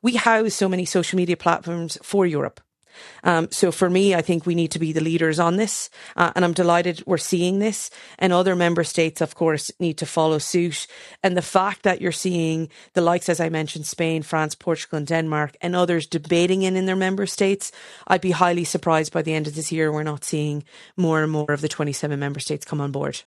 MEP for Midlands North West Maria Walsh says other EU countries will bring in similar measures this year………